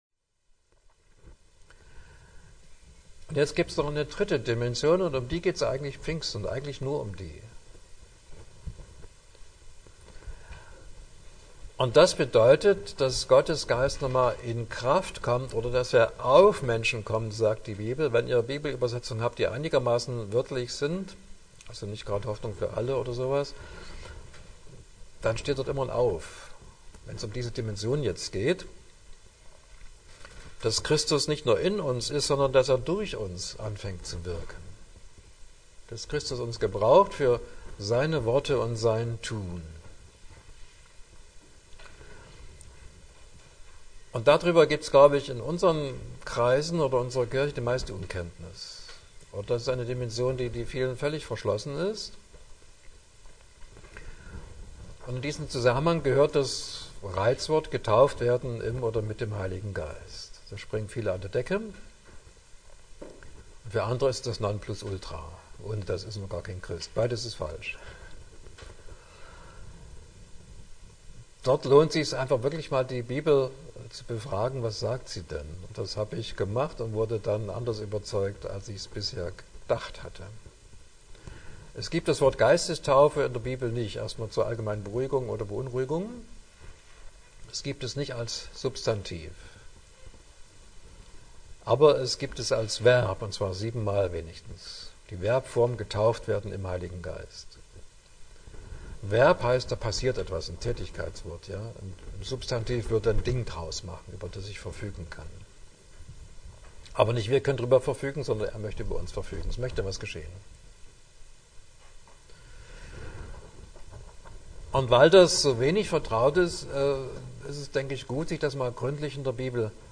Teil 2 des 2. Vortrages – ca. 43 Minuten